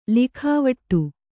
♪ likhāvaṭṭu